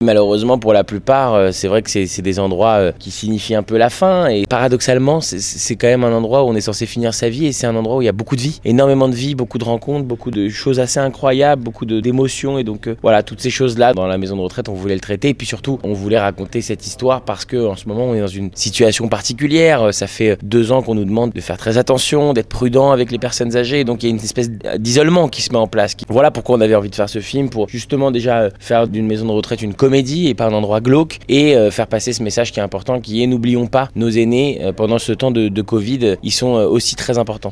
A l’affiche on retrouve Kev Adams qui joue le rôle d’un jeune qui, pour éviter de se retrouver en prison, doit se résoudre à effectuer 300 heures de travaux d’intérêts général dans une maison de retraite. Un film qui se veut à la fois comique et humaniste. Kev Adams est venu présenter ce film en avant-première en Haute-Savoie, notamment au Ciné Mont-Blanc à Sallanches.